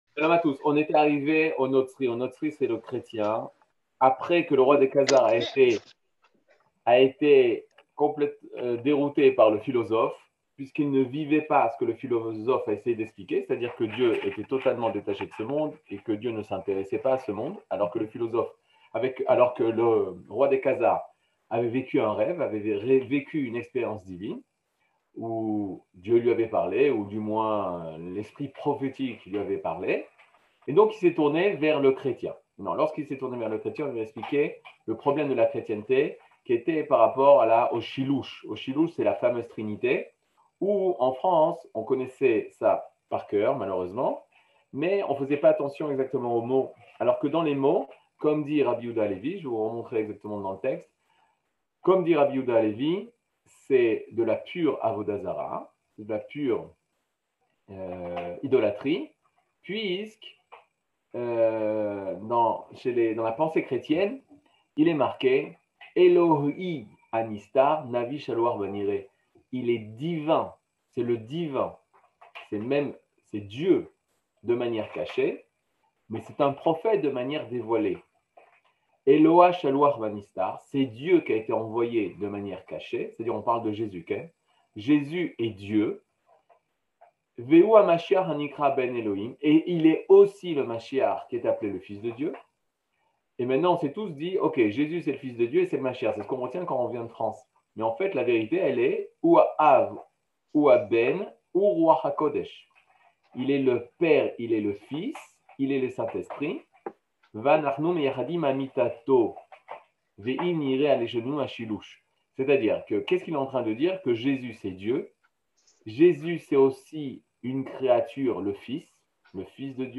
Catégorie Le livre du Kuzari partie 8 00:58:00 Le livre du Kuzari partie 8 cours du 16 mai 2022 58MIN Télécharger AUDIO MP3 (53.1 Mo) Télécharger VIDEO MP4 (108.22 Mo) TAGS : Mini-cours Voir aussi ?